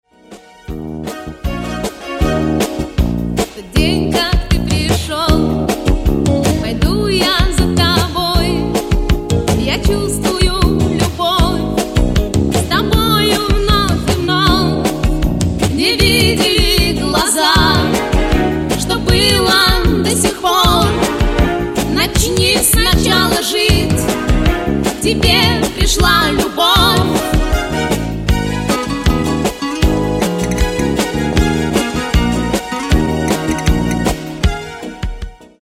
• Качество: 320, Stereo
гитара
женский вокал